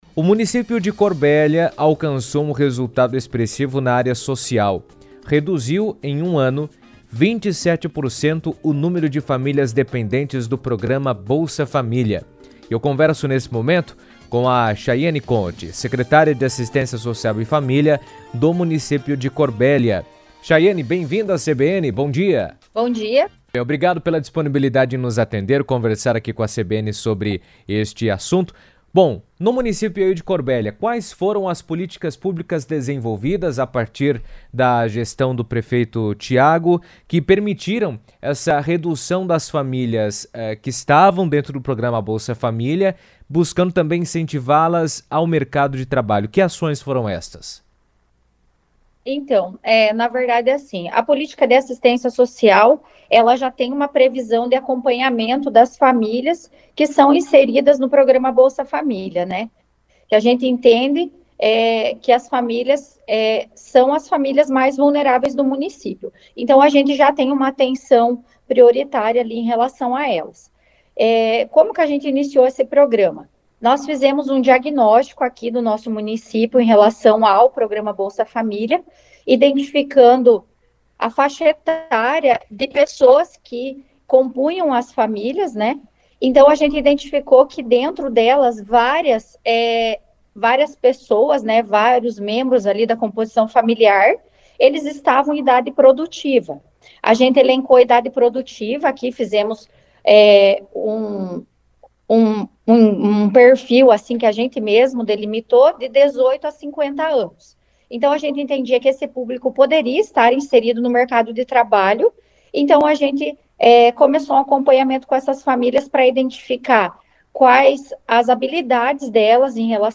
O resultado é atribuído a ações voltadas à geração de emprego e renda e ao acompanhamento social das famílias. O tema foi comentado pela secretária de Assistência Social e Família, Chayene Conti, que falou sobre o assunto em entrevista à CBN.